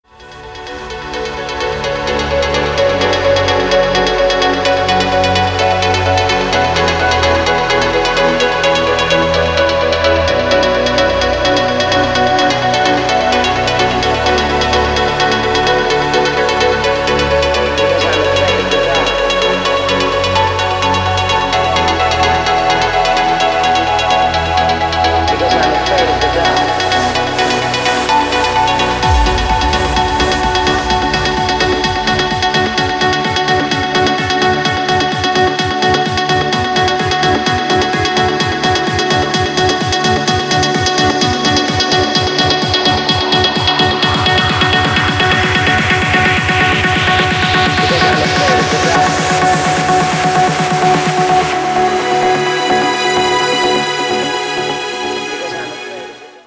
• Качество: 320, Stereo
атмосферные
Electronic
спокойные
без слов
нарастающие
house
пробуждающие
Спокойная house музыка для будильника